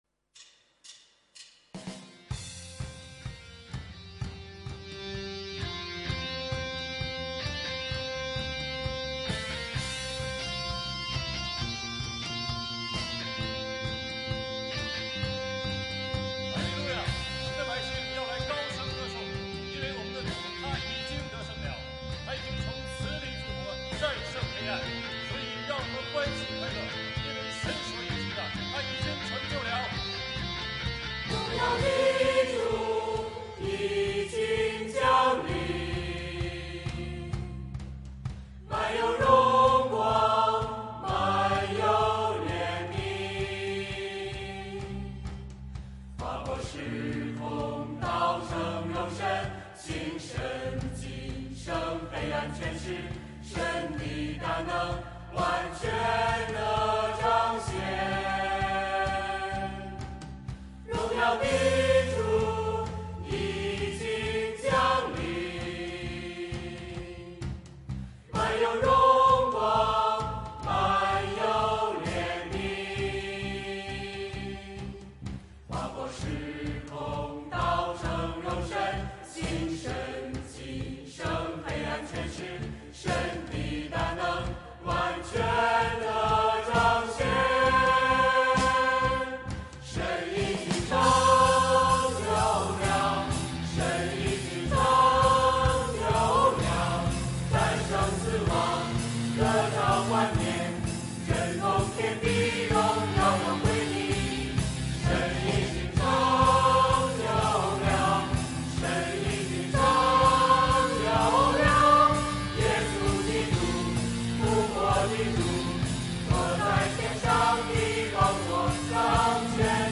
团契名称: 清泉诗班 新闻分类: 诗班献诗 音频: 下载证道音频 (如果无法下载请右键点击链接选择"另存为") 视频: 下载此视频 (如果无法下载请右键点击链接选择"另存为")